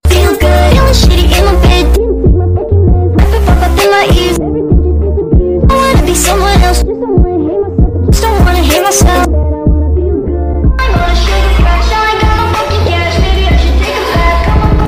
i used voice changer on the app#AudioLab